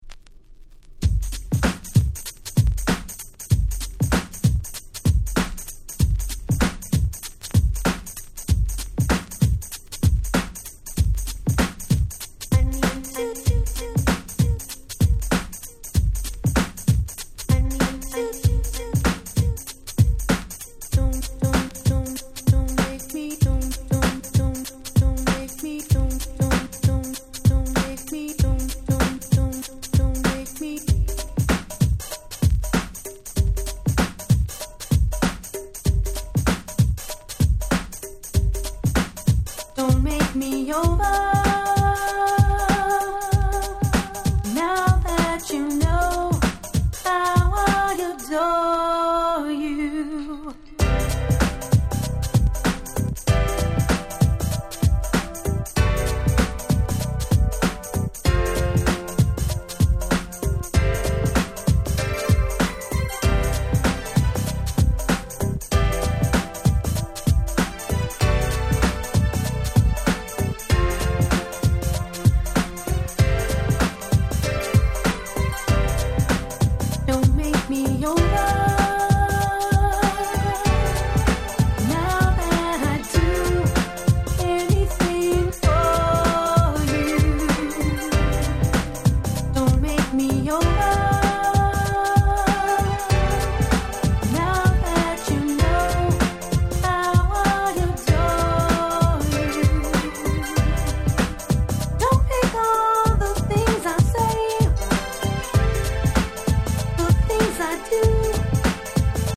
89' Super Hit R&B !!!
Ground Beat Classic !!